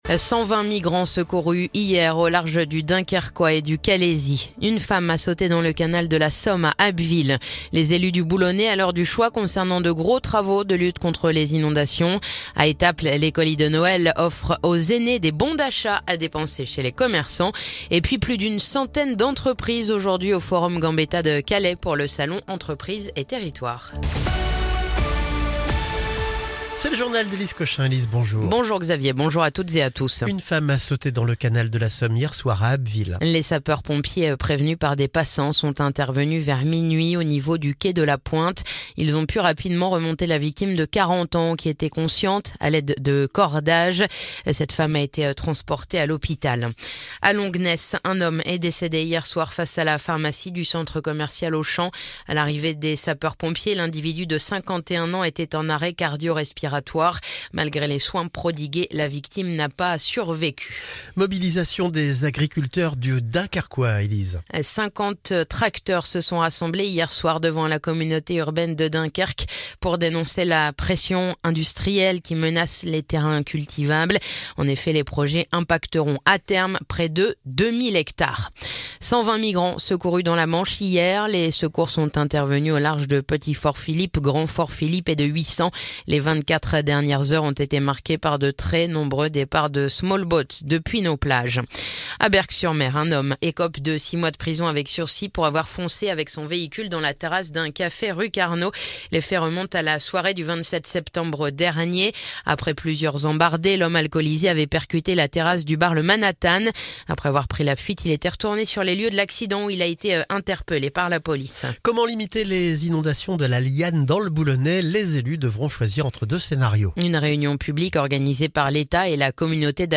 Le journal du vendredi 13 décembre